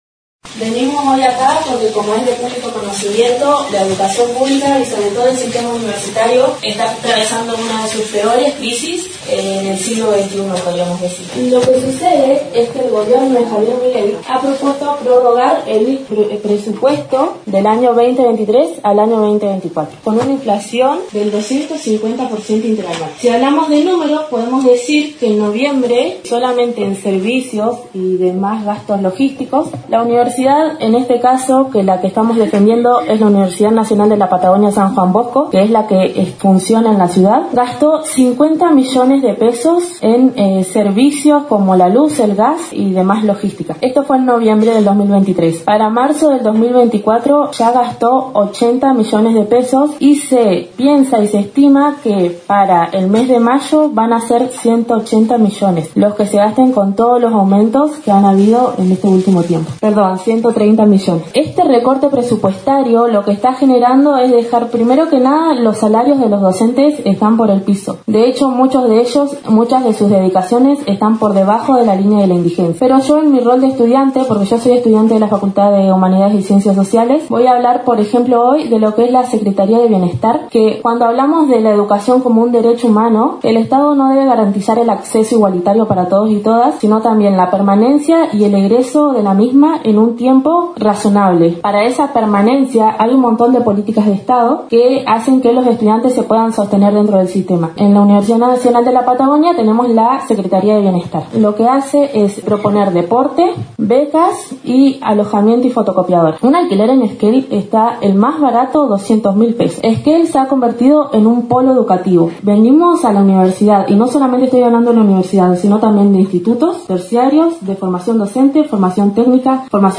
En el marco de la cuarta sesión ordinaria del Concejo Deliberante, estudiantes de la Universidad Nacional de la Patagonia San Juan Bosco sede Esquel, presentaron mediante la Banca del Vecino un proyecto para que el Concejo Deliberante se declare en defensa de la educación pública, la ciencia y el sistema universitario nacional.